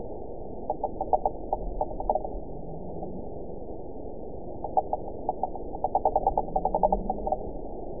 event 910393 date 01/21/22 time 07:40:54 GMT (3 years, 4 months ago) score 7.09 location TSS-AB05 detected by nrw target species NRW annotations +NRW Spectrogram: Frequency (kHz) vs. Time (s) audio not available .wav